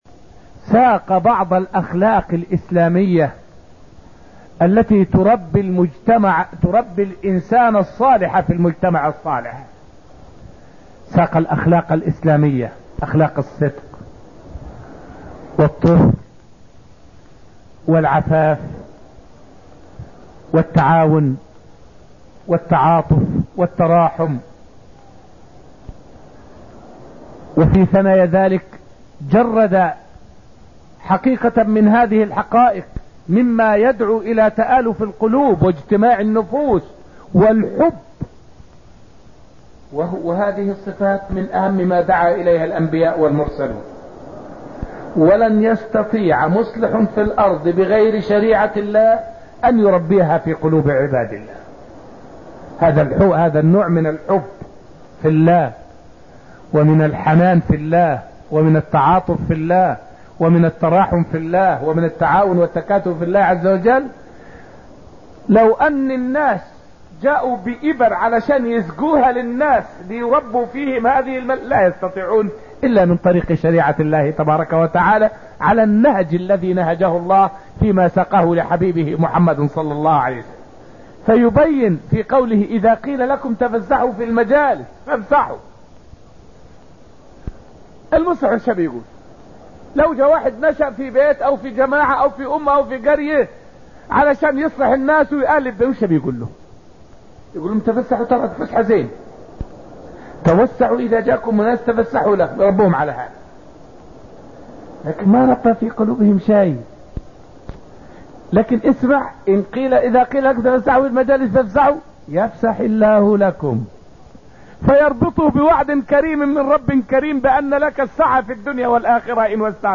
فائدة من الدرس العاشر من دروس تفسير سورة المجادلة والتي ألقيت في المسجد النبوي الشريف حول بعض الأخلاق الإسلامية في سورة المجادلة.